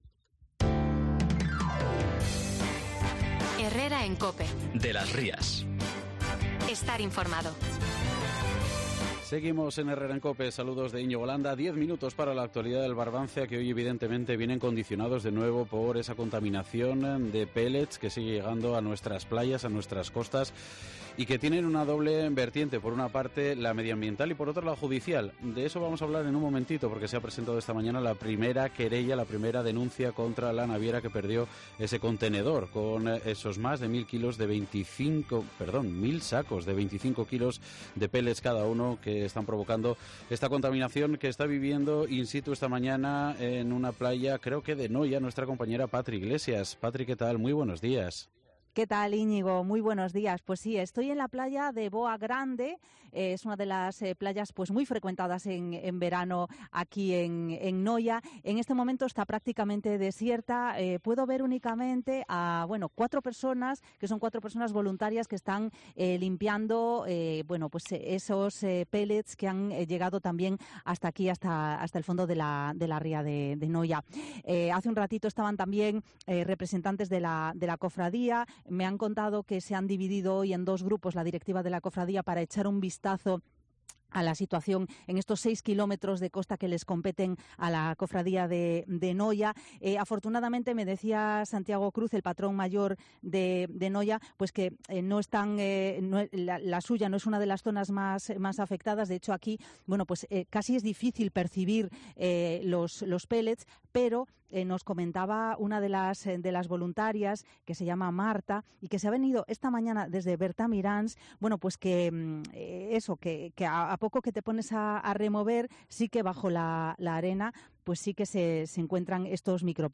dende a praia de Boa, en Noia.